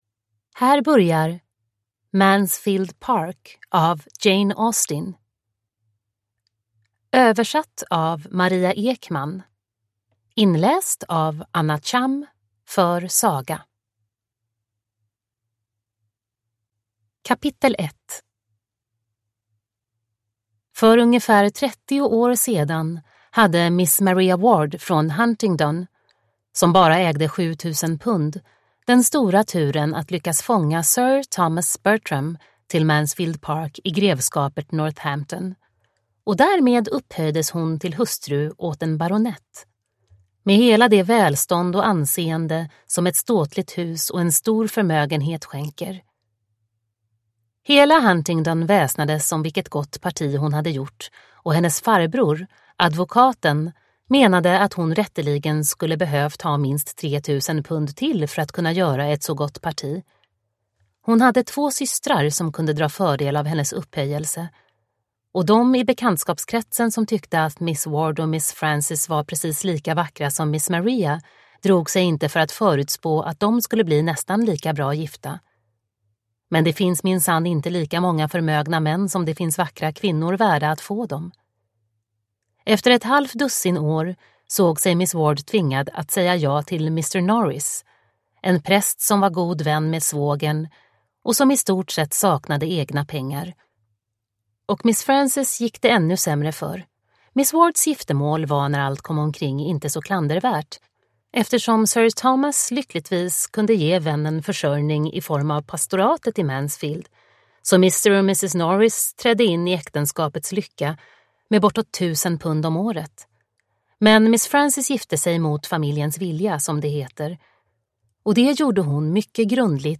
Mansfield Park / Ljudbok